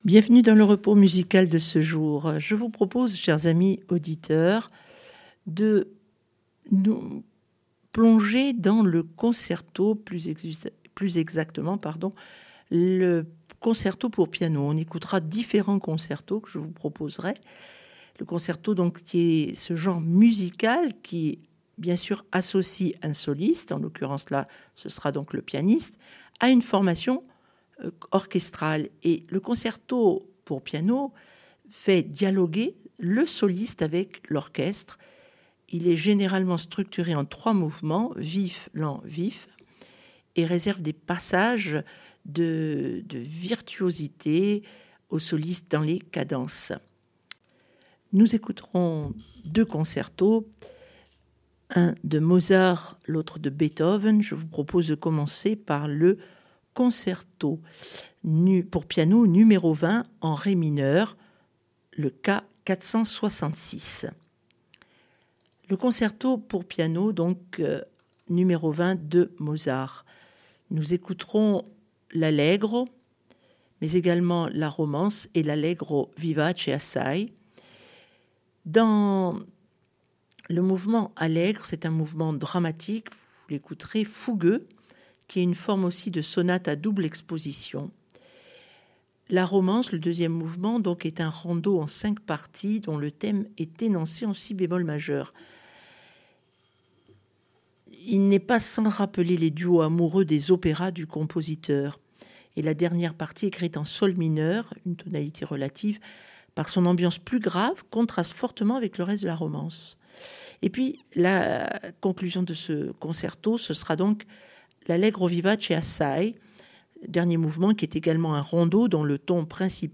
concertos vari